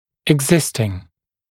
[ɪg’zɪstɪŋ][иг’зистин]существующий, имеющийся